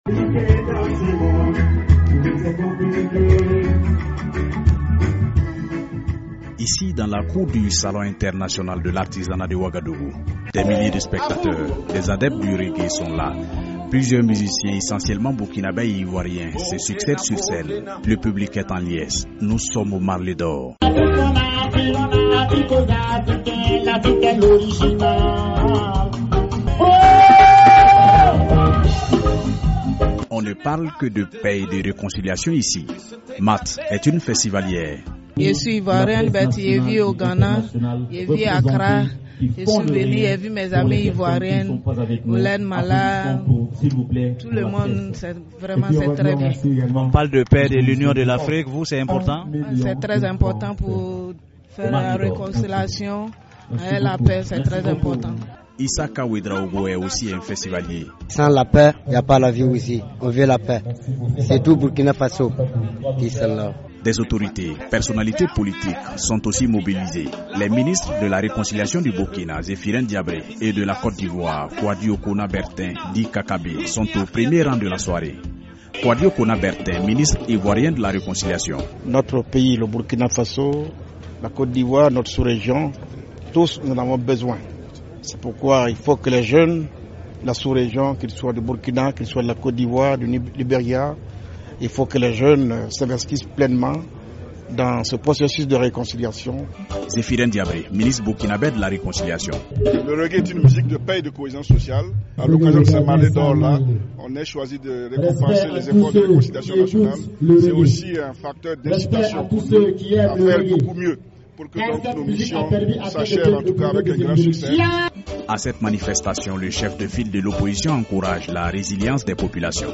Dans la cour du Salon international de l’artisanat de Ouagadougou, des milliers de spectateurs, des adeptes du reggae se sont réunis.
Le public est en liesse aux Marley d’Or.